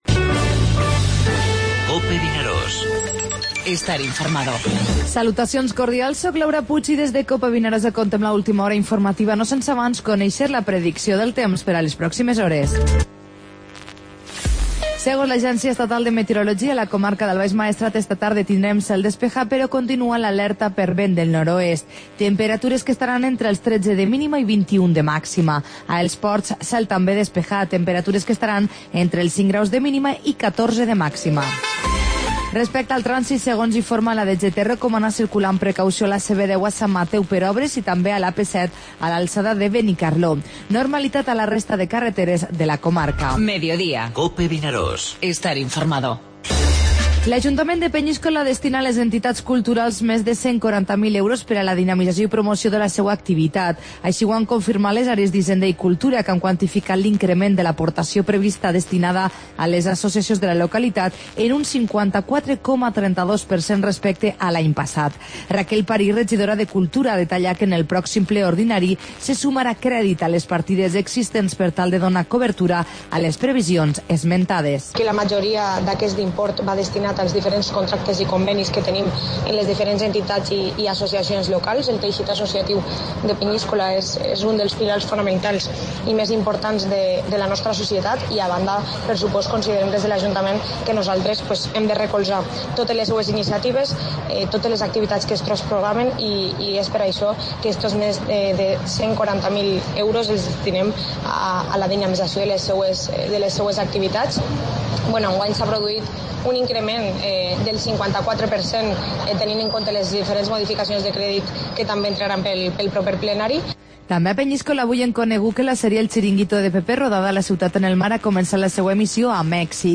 Informativo Mediodía COPE al Maestrat (dimarts 7 de març)